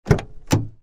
Открыли дверь буханки